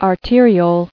[ar·te·ri·ole]